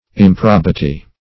Improbity \Im*prob"i*ty\, n. [L. improbitas; pref. im- not +